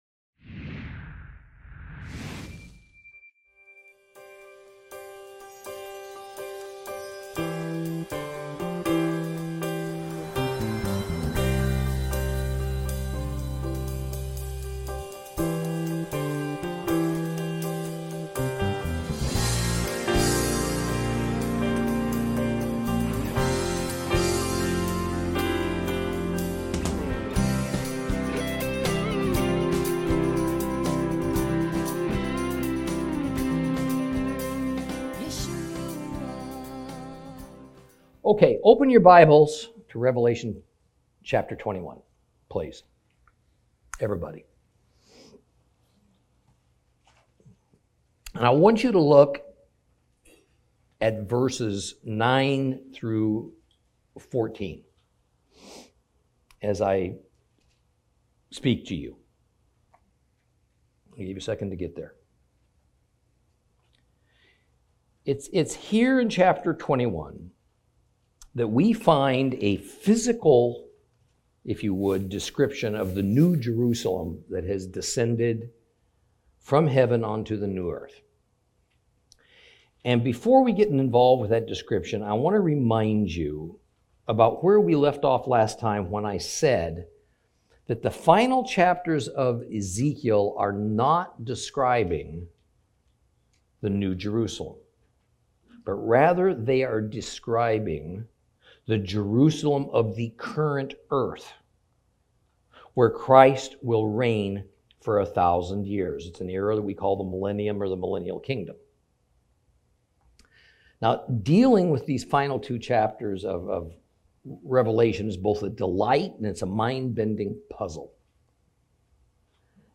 Lesson 50 Ch21 - Torah Class